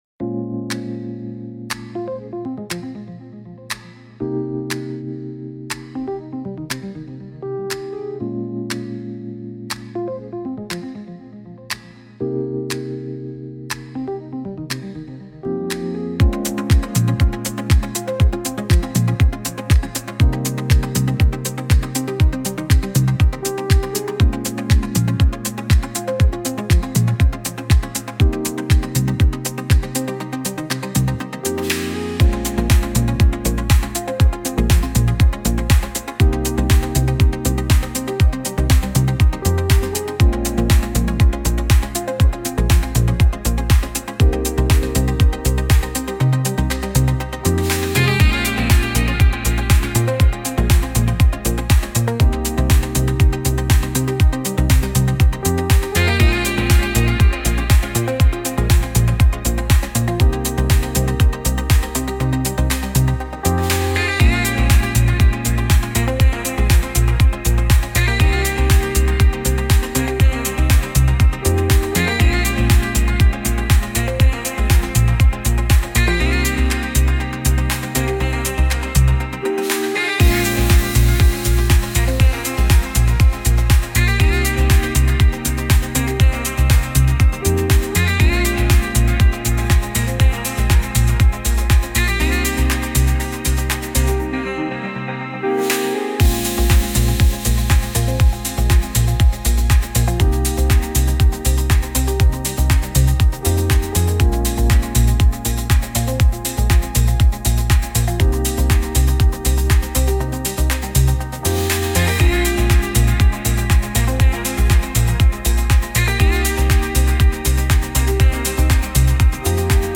Instrumental -Real Liberty Media DOT xyz - 4.00 mins